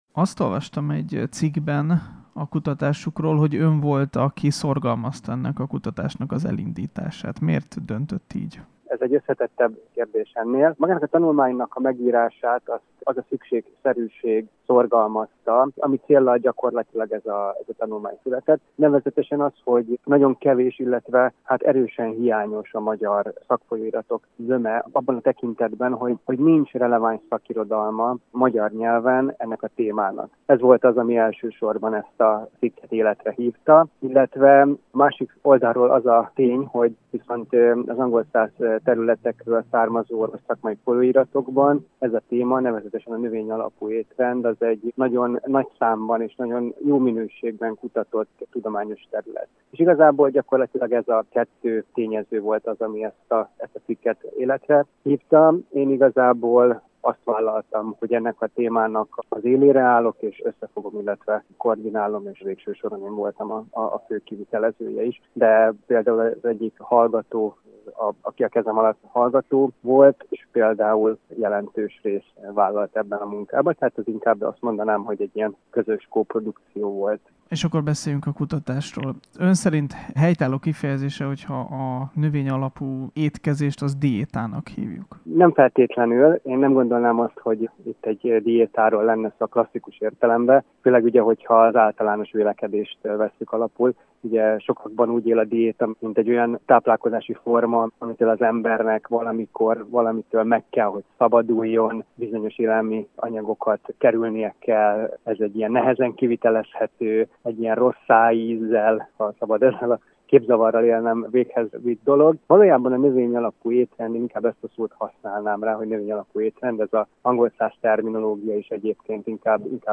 dietetikussal